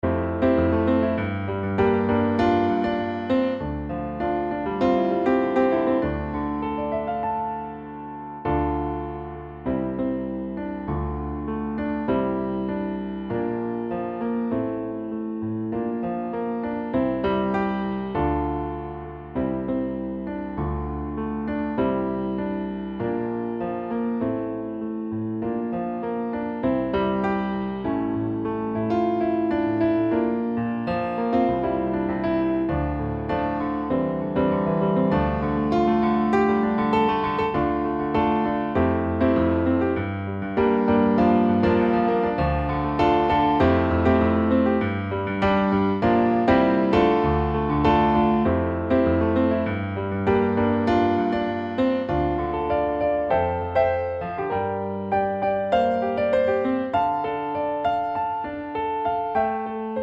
Piano Only